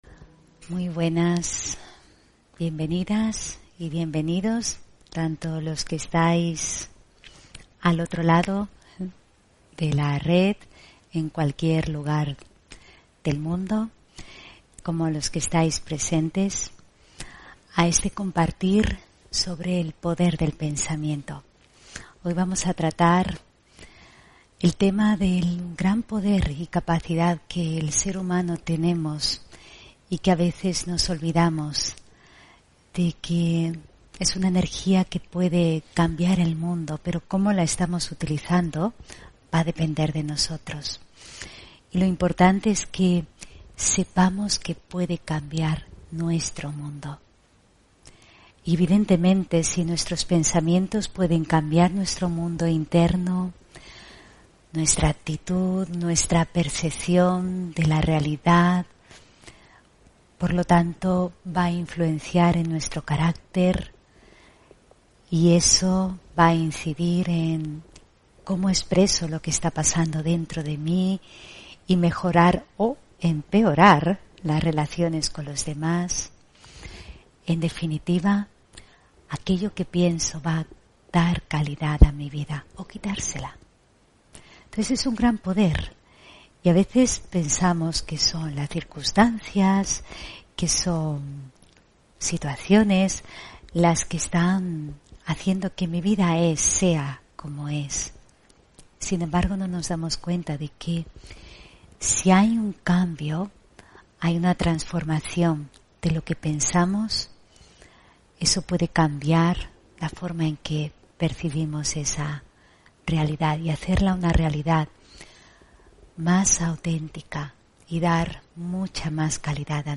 Meditación Raja Yoga y charla: El poder del pensamiento (14 Julio 2021) On-line desde Sevilla